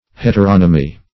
Search Result for " heteronomy" : The Collaborative International Dictionary of English v.0.48: Heteronomy \Het`er*on"o*my\, n. 1.